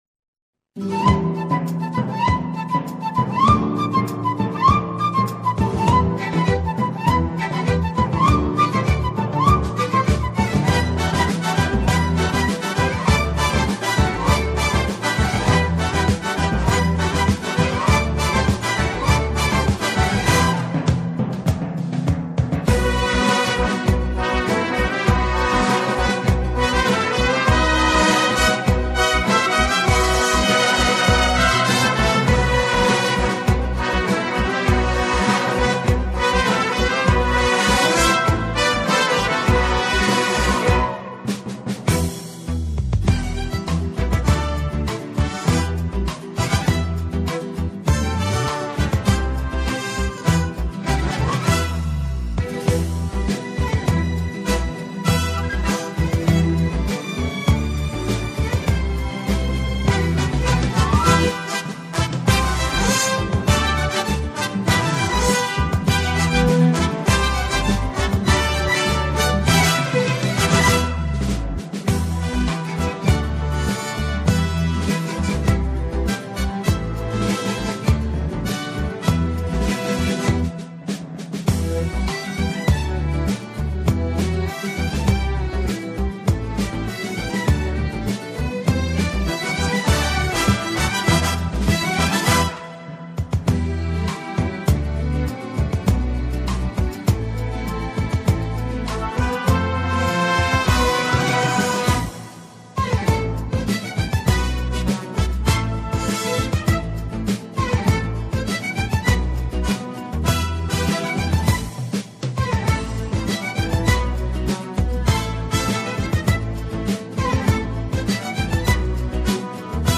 سرودهای ورزشی
بی‌کلام